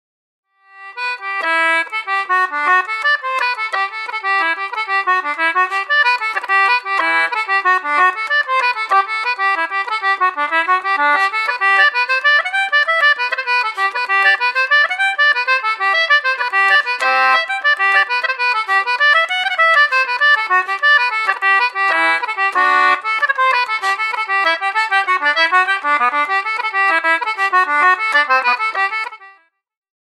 Reels: The Nine Pint Coggie/Paddy Kelly's